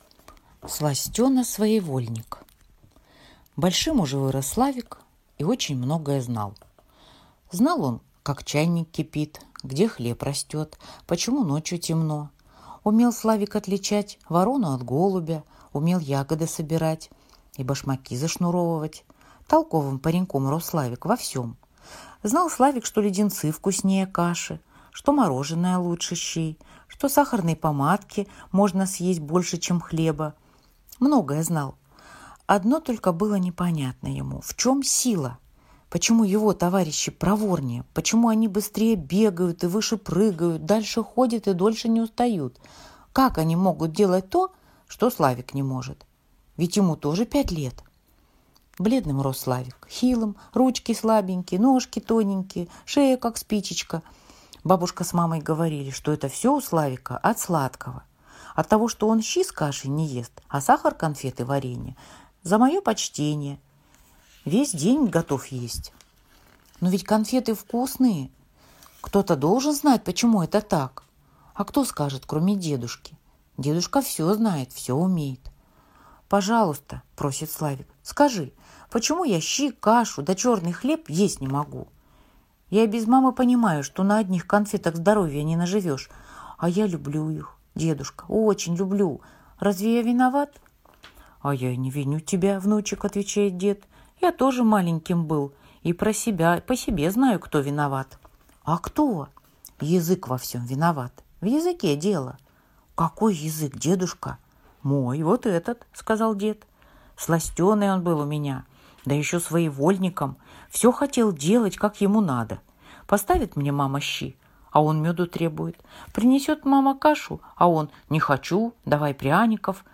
Аудиорассказ «Сластена-своевольник»